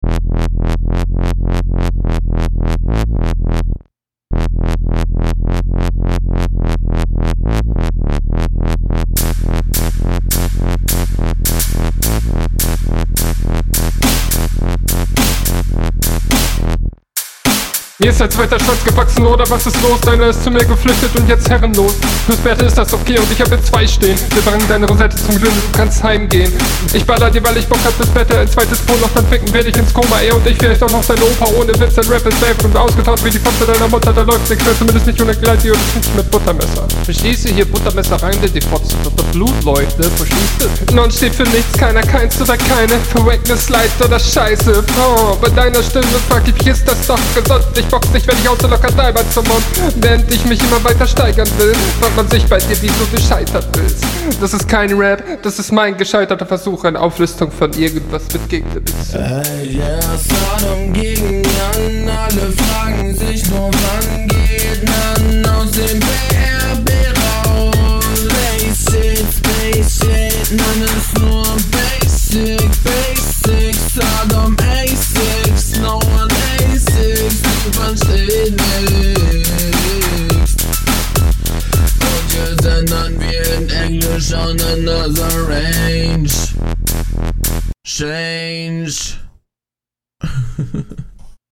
Uuh der Beat ist nice.
Leider versteht man kaum etwas vom Text, hört sich aber besser an wenn man schneller …